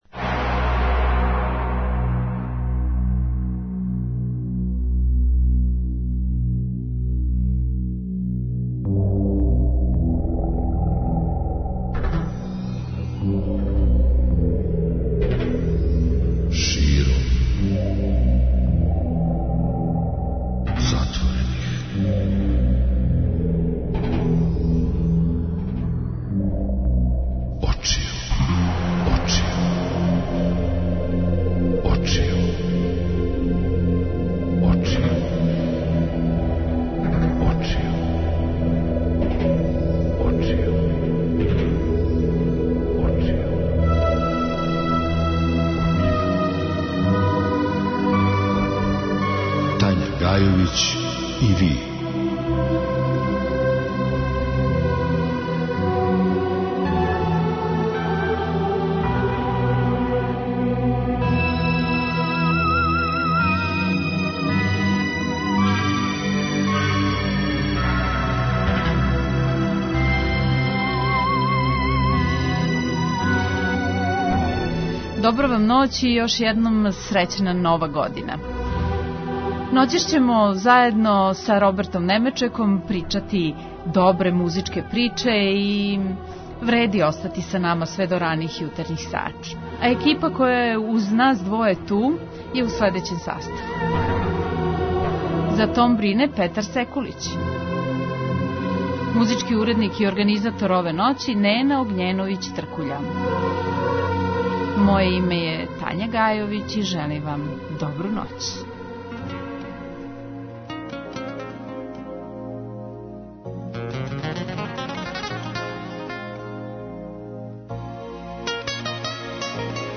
Музичке приче